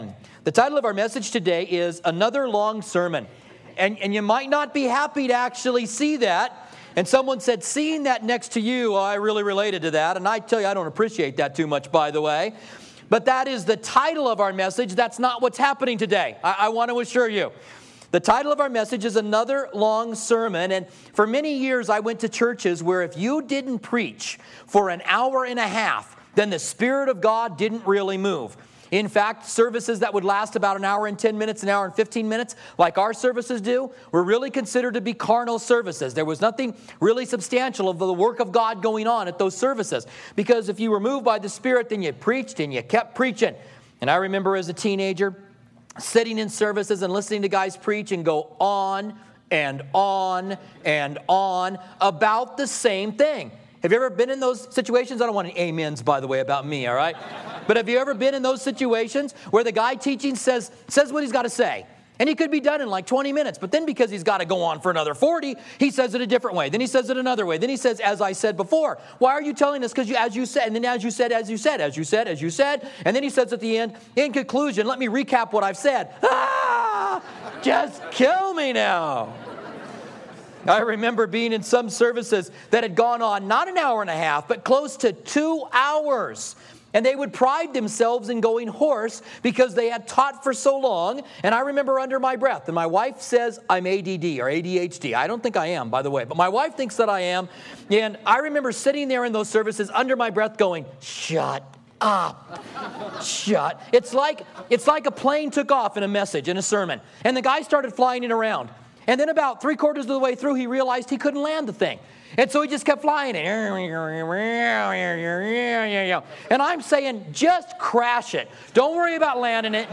Another Long Sermon - Job 32-37 - Calvary Tucson Church